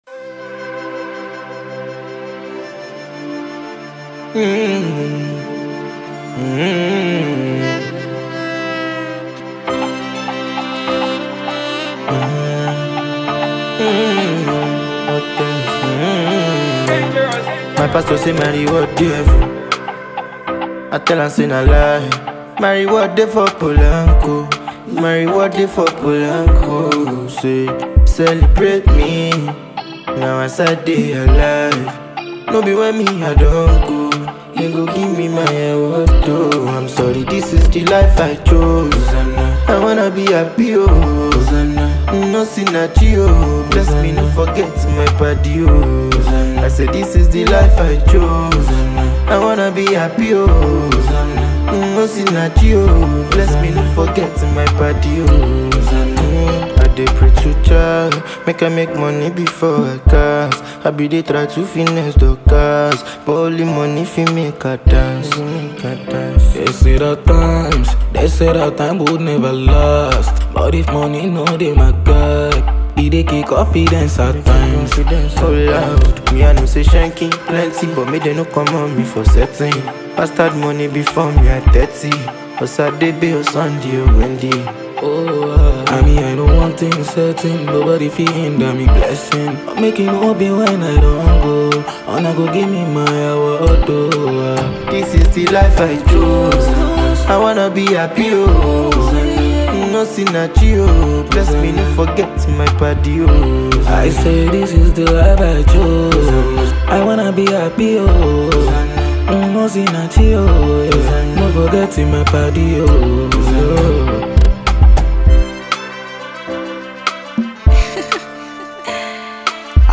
With its infectious melody and catchy lyrics